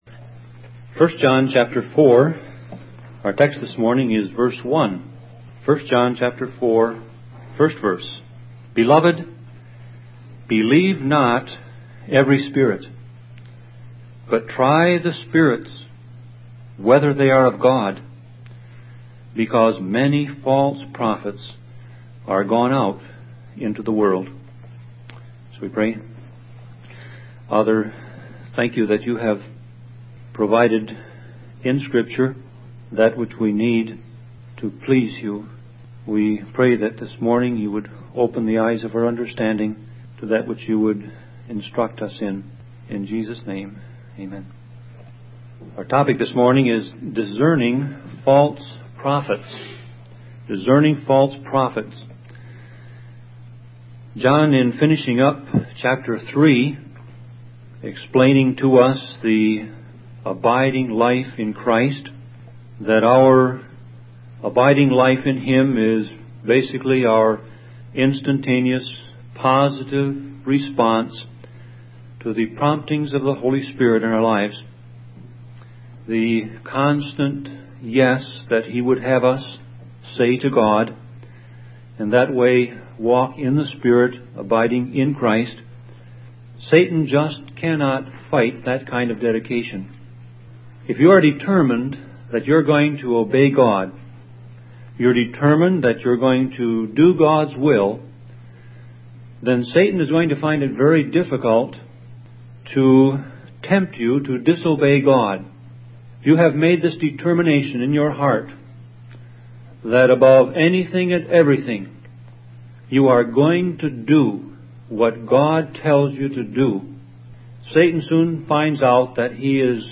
Sermon Audio Passage